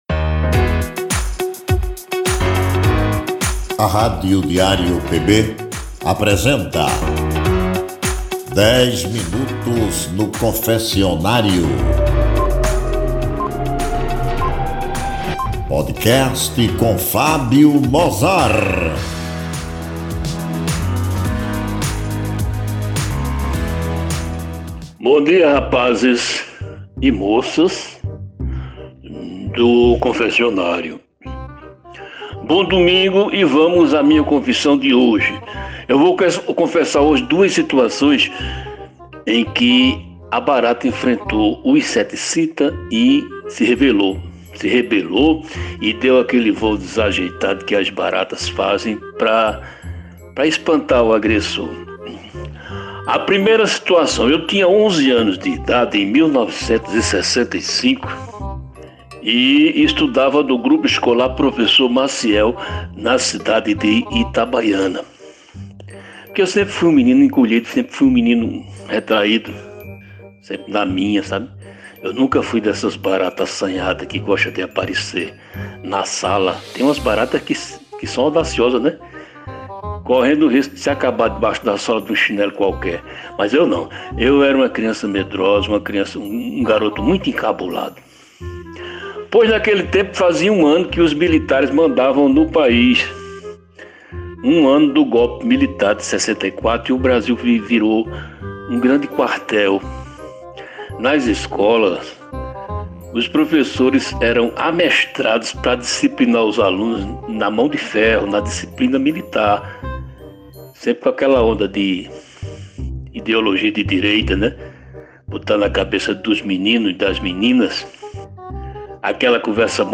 é um programa com um papo descontraído, às vezes incomum, sobre as trivialidades do nosso cotidiano.